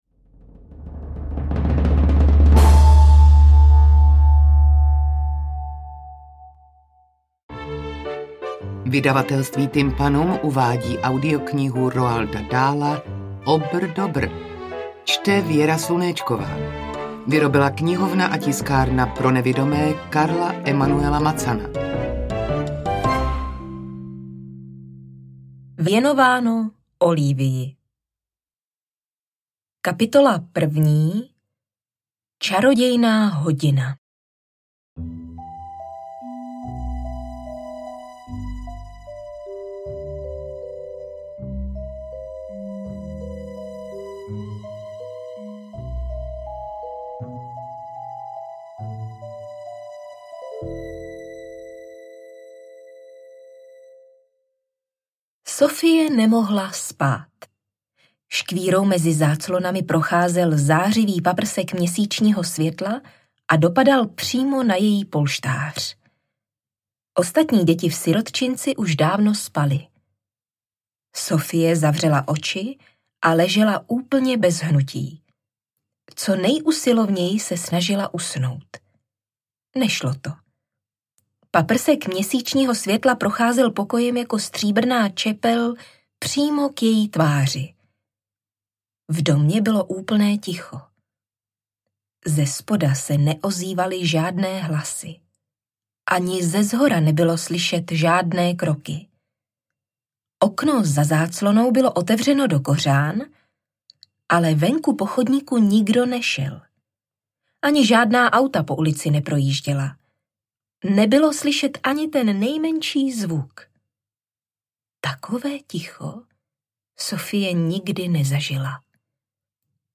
Audiokniha je...